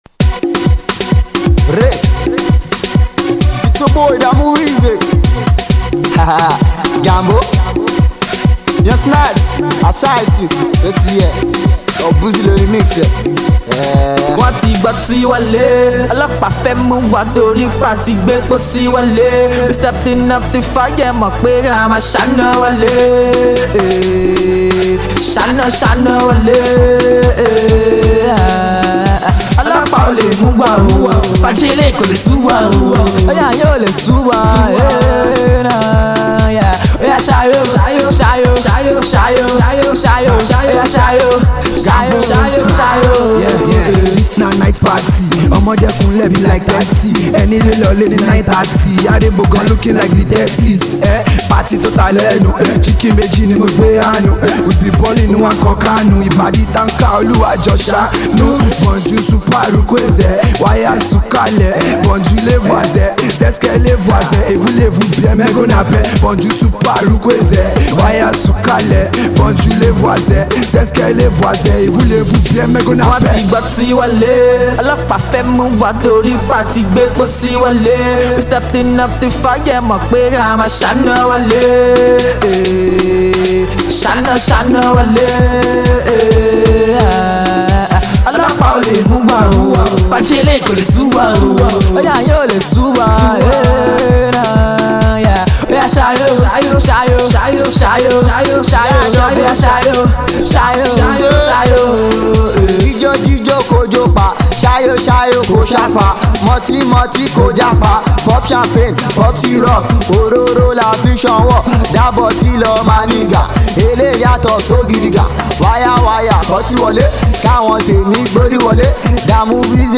club and street banging dope jam